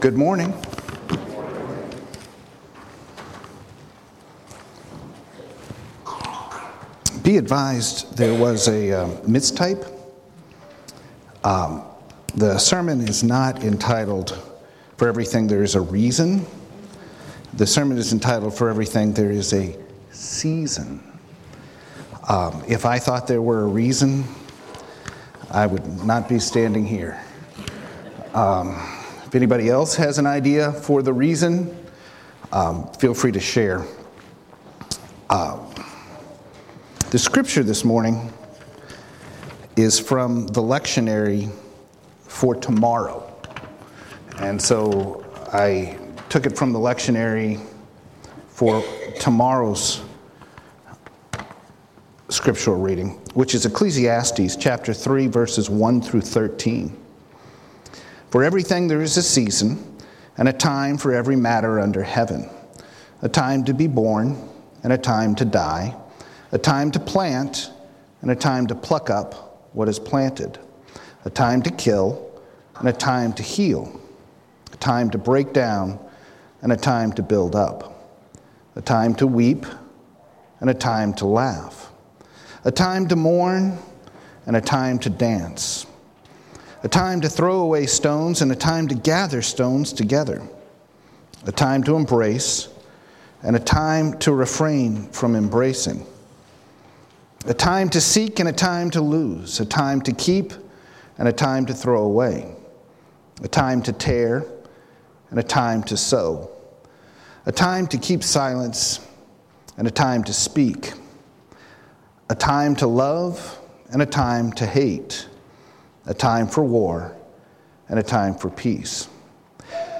Sermon for Dec. 31, 2017